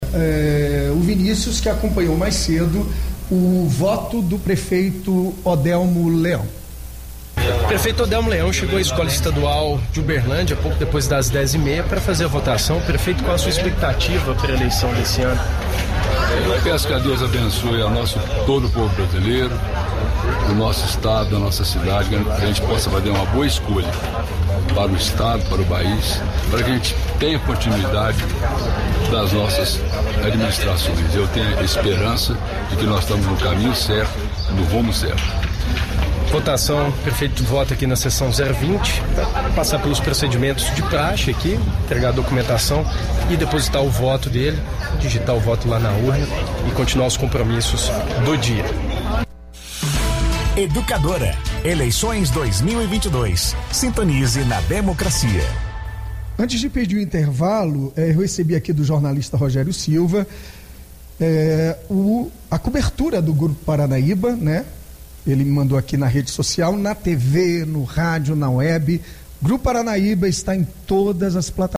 – Entrevista com prefeito Odelmo Leão, fala das suas expectativas sobre as eleições de 2022, é com muita tranquilidade, paz e progresso para o País, cidade, estado. Disse também que vai continuar trabalhando em bem ao povo de Uberlândia.